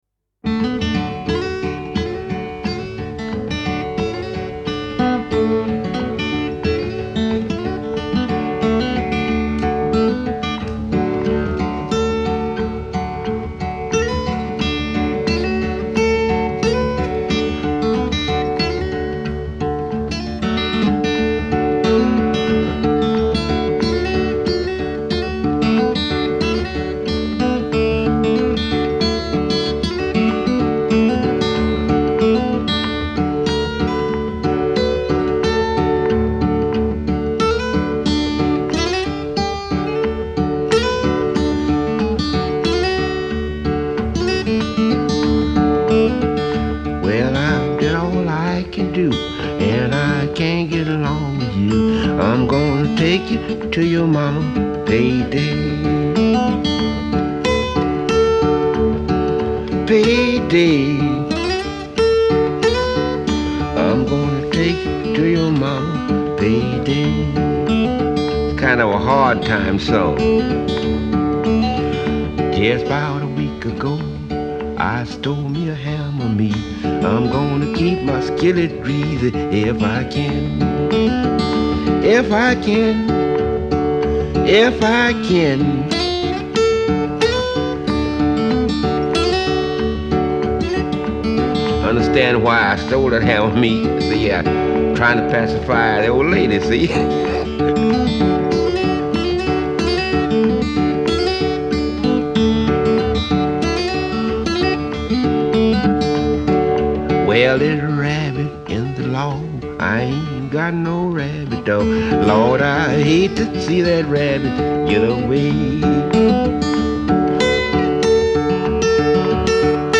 Genre : Rock, Blues, Folk